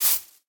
Minecraft Version Minecraft Version latest Latest Release | Latest Snapshot latest / assets / minecraft / sounds / block / nether_sprouts / step5.ogg Compare With Compare With Latest Release | Latest Snapshot